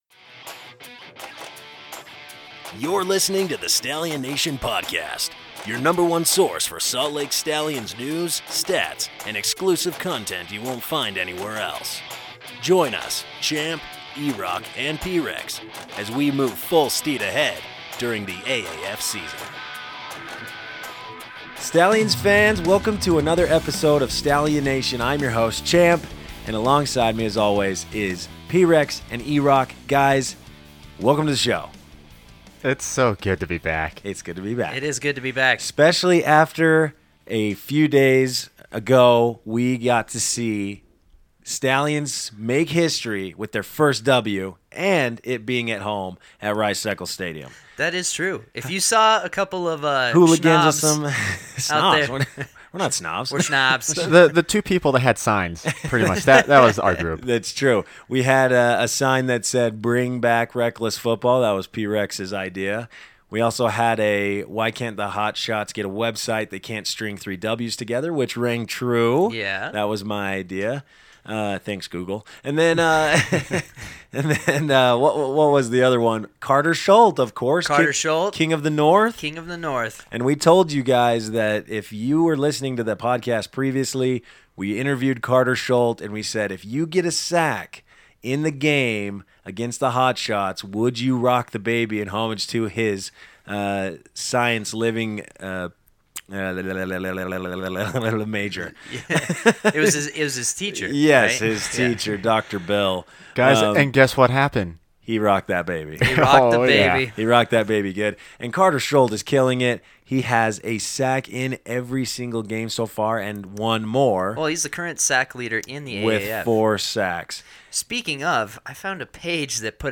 Floor Mop to the Top | Interview